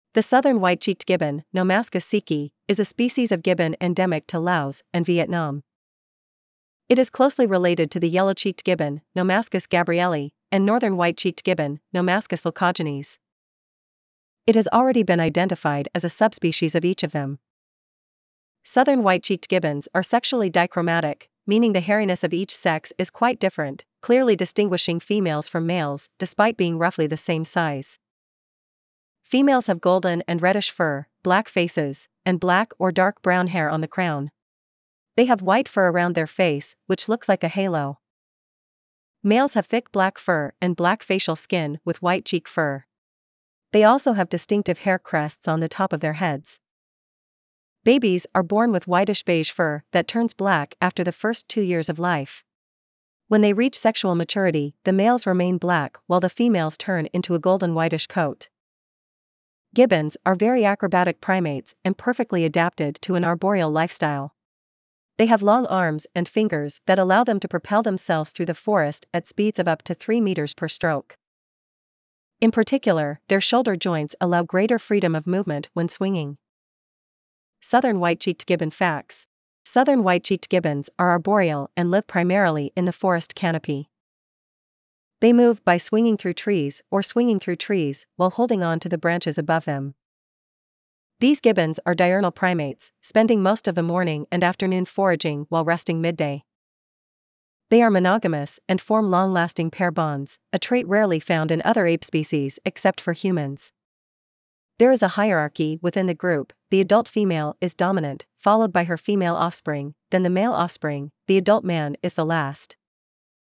Southern White-cheeked Crested Gibbon
southern-white-cheeked-gibbon.mp3